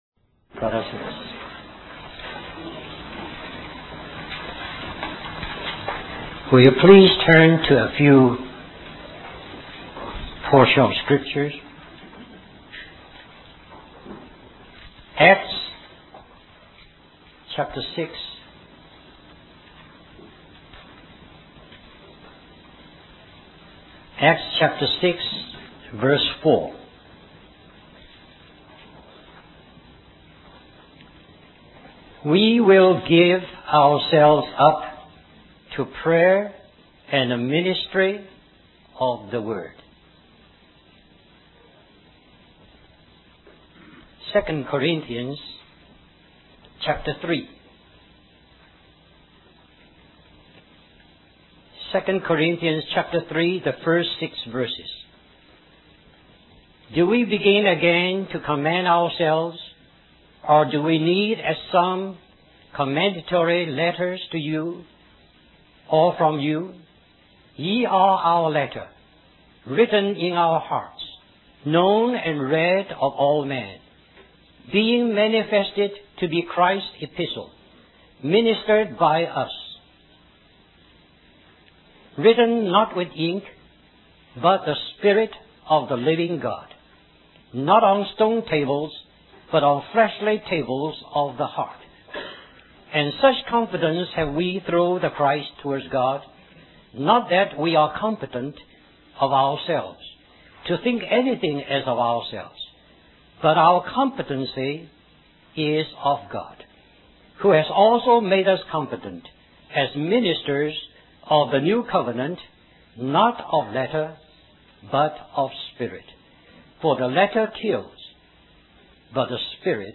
Florida Leadership Conference